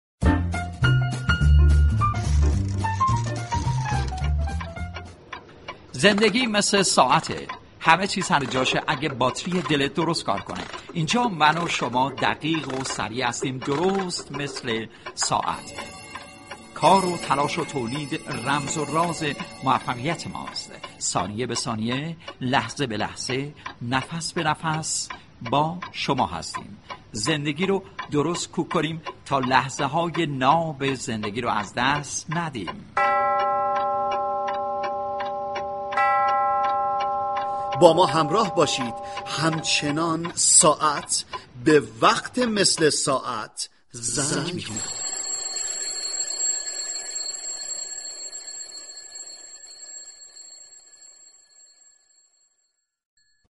به گزارش روابط عمومی رادیو صبا، «مثل ساعت» عنوان برنامه تركیبی-نمایشی است كه با محوریت شعار سال روی آنتن می رود و چالش های موجود در مسیر تولید در صنایع مختلف را با نگاهی طنز مورد بررسی قرار می دهد .
نمایش های كوتاه طنز و گزارش های طنز از جمله بخش های این برنامه است.